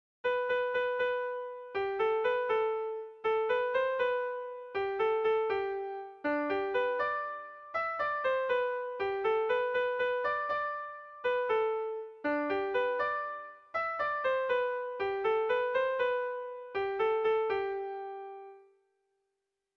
Erromantzea
ABDEDE2